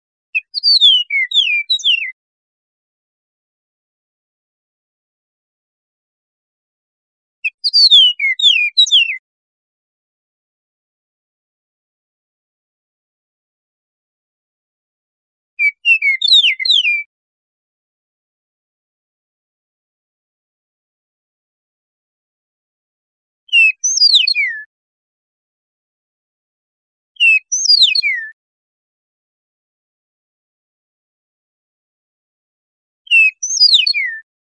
Eastern Meadowlark | Ask A Biologist
Download Sound Bird Sound Type: Whistling Sex of Bird: Male Sonogram Large: Sonogram Zoom: Download Sound Bird Sound Type: Whistling Sex of Bird: Male Sonogram Large: There are no sonograms saved for this audio.